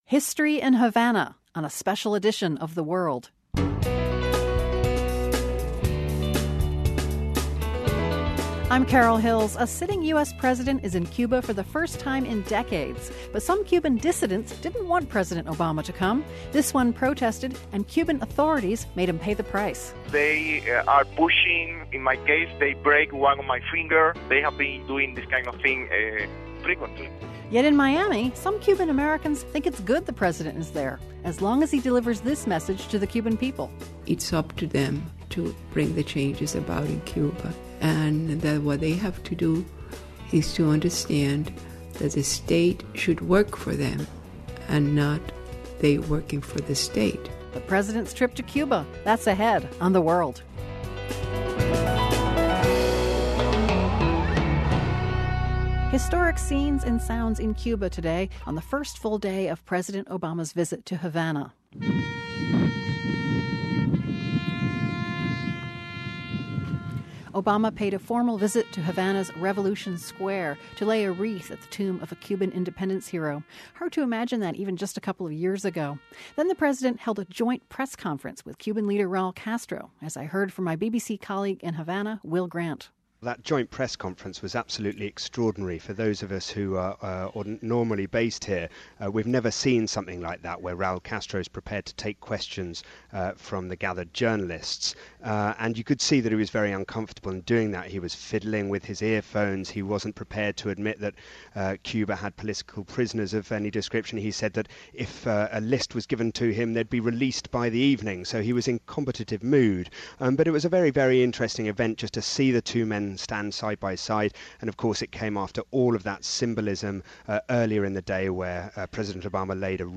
Plus, we take a trip to Little Havana in Miami to gauge reaction to the trip among Cuban-Americans.